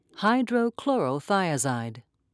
(hye-droe-klor-oh-thye'a-zide)